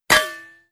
dummyBodyHit.wav